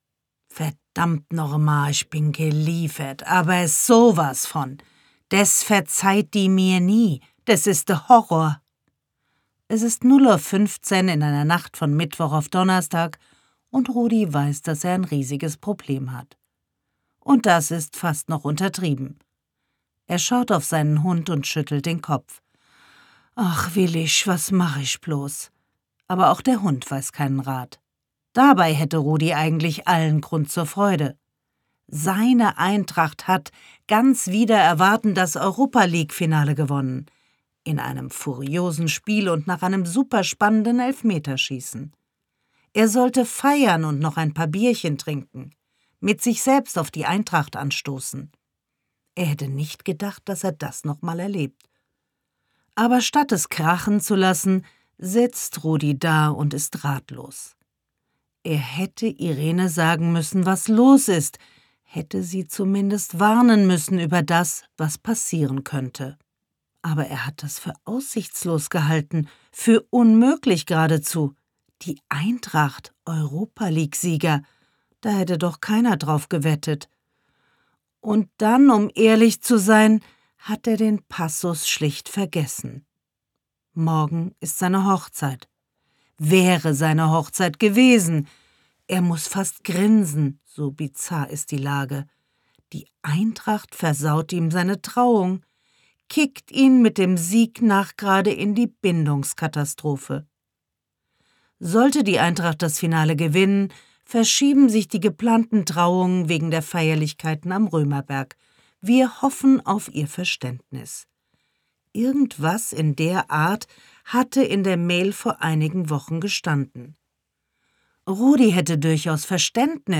Getraut Roman | SPIEGEL Bestseller-Autorin Susanne Fröhlich (Autor) Susanne Fröhlich (Sprecher) Audio Disc 2023 | 2.